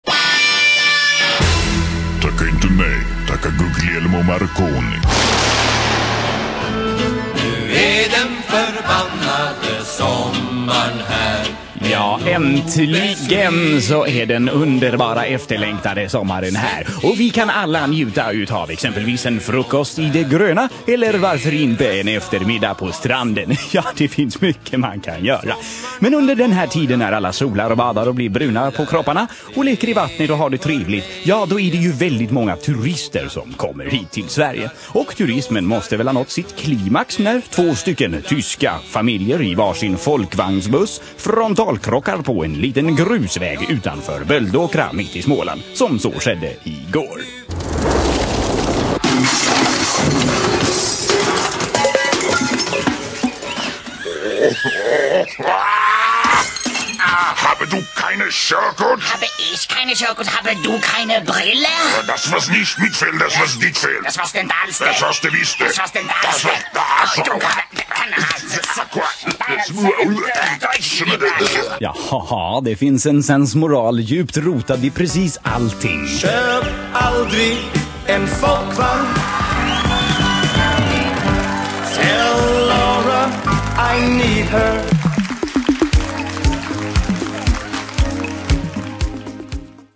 Totalt VÄRDELÖSA sketscher
Lo-fi filerna är samma som originalet men i mono och lite sämre ljudkvalisort.